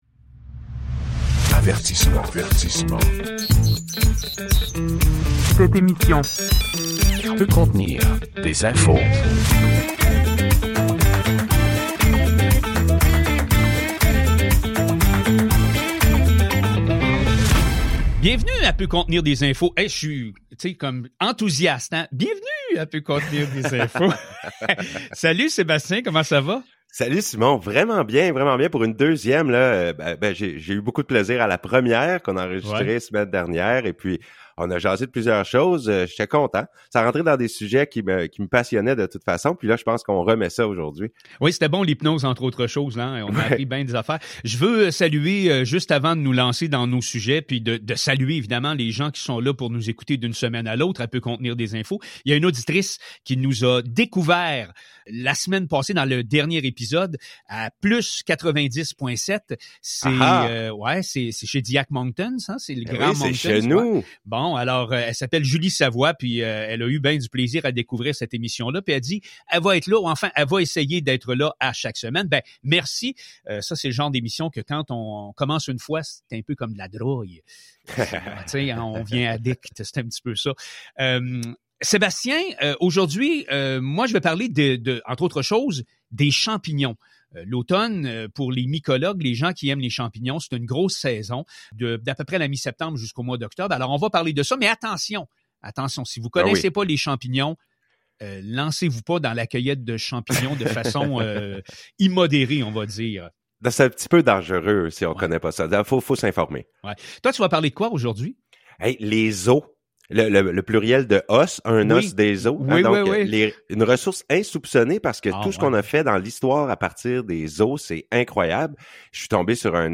une entrevue